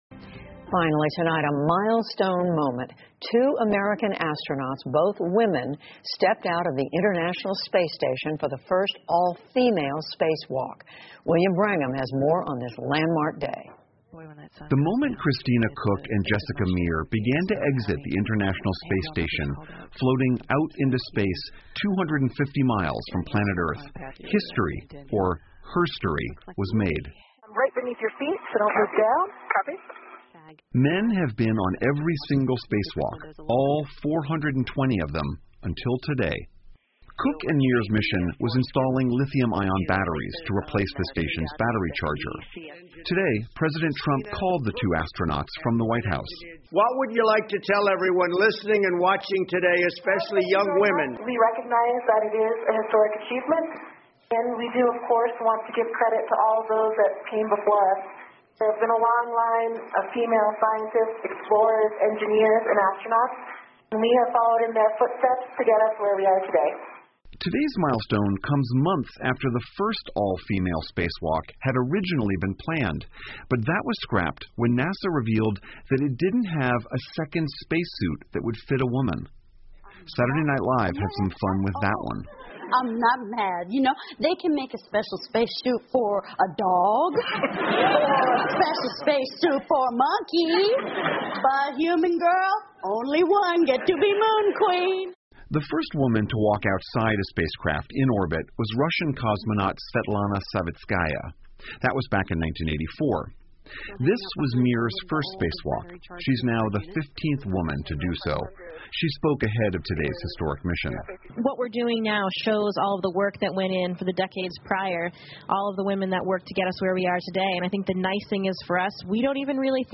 在线英语听力室PBS高端访谈:女性的首次太空漫步的听力文件下载,本节目提供PBS高端访谈社会系列相关资料,内容包括访谈音频和文本字幕。